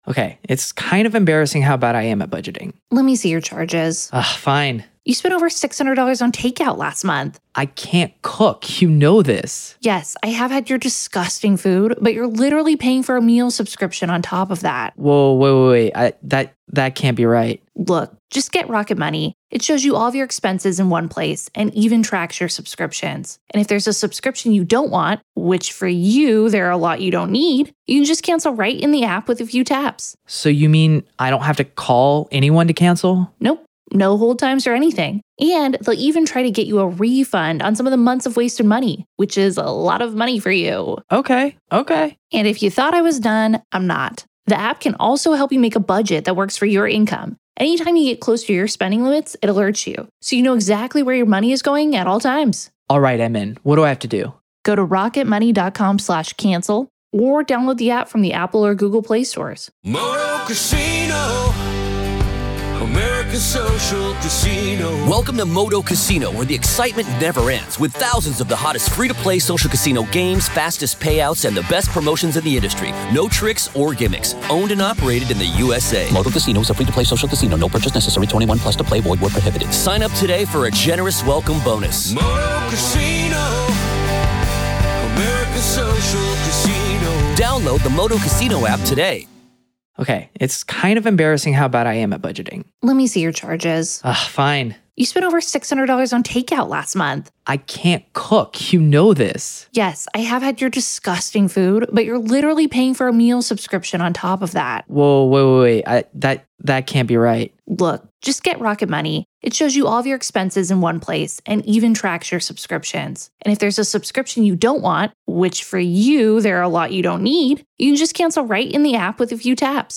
Every episode beams you directly into the heart of the courtroom, with raw, unedited audio from testimonies, cross-examinations, and the ripple of murmurs from the gallery.